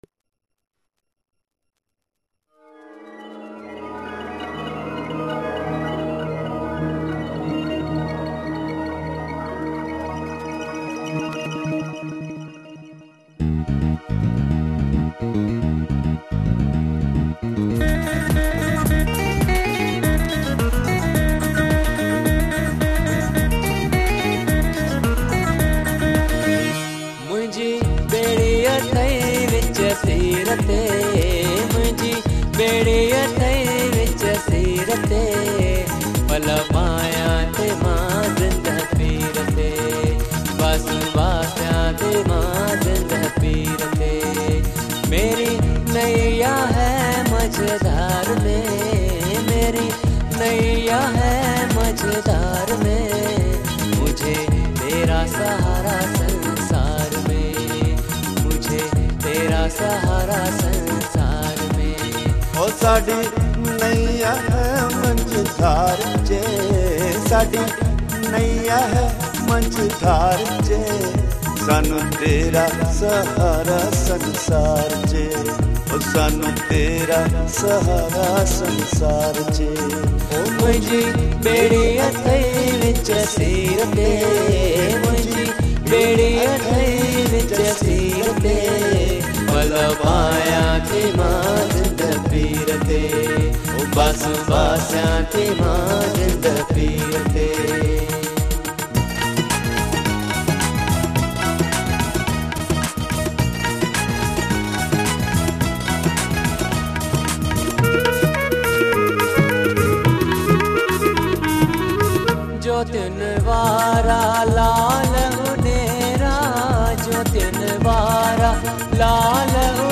An Authentic Ever Green Sindhi Song Collection